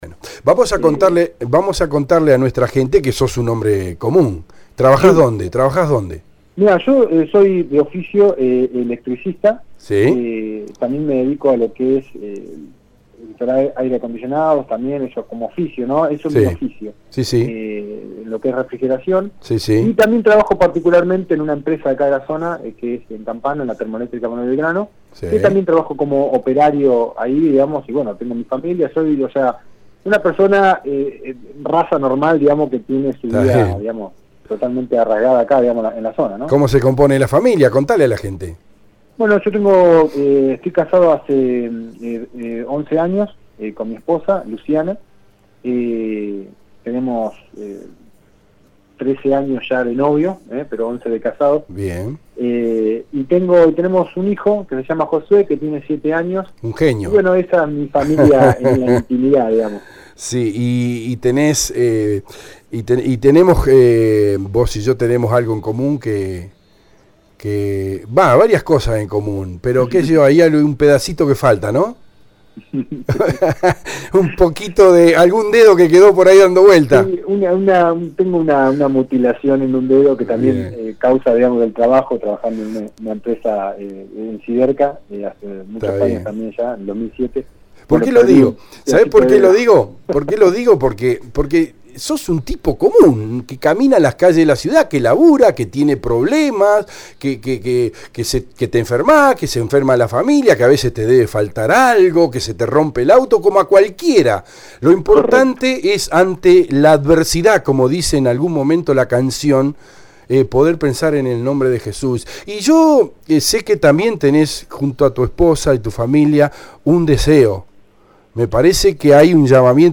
Al finalizar la charla en el programa Con Zeta de radio EL DEBATE, escuchamos con los oyentes el tema TU NOMBRE JESÚS que se estrenó horas antes y que podes ver al pie de esta nota.